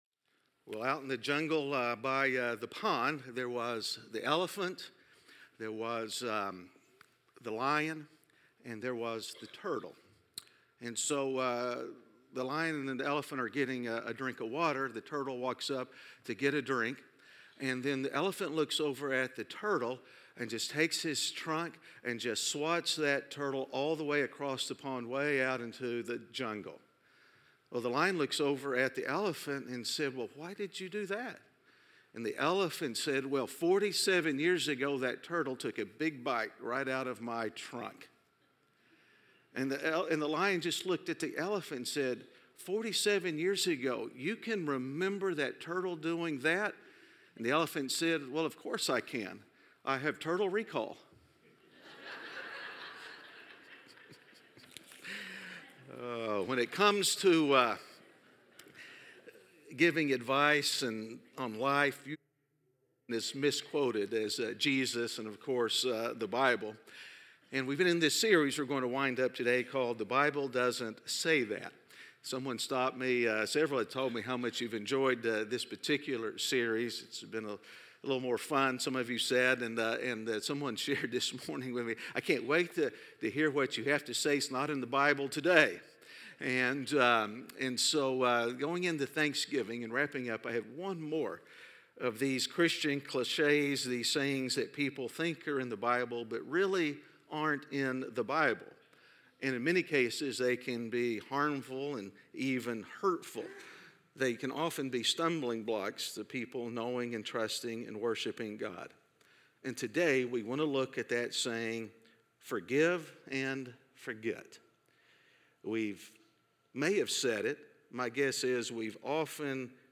A message from the series "God Wrote a Book."